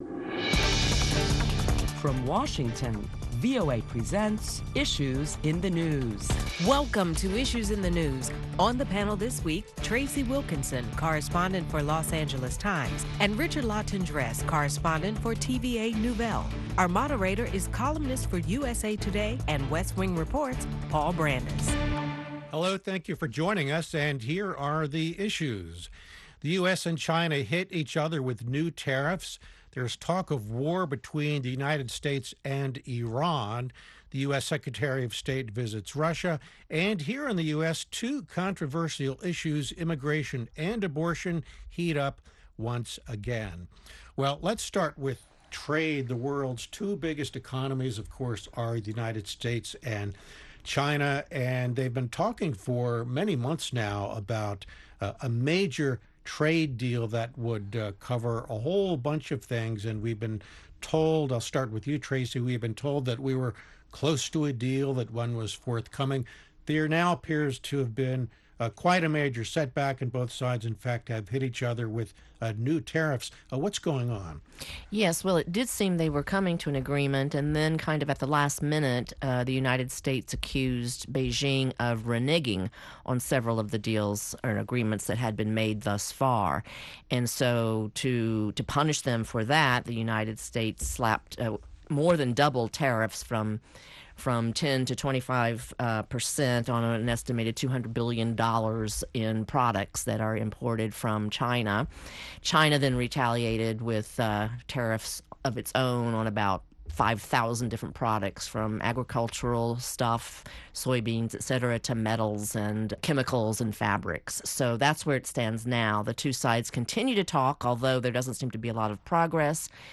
Listen to a panel of prominent Washington journalists as they discuss the week's headlines including the U.S. trade war with China, and it’s possible impact on the global economy.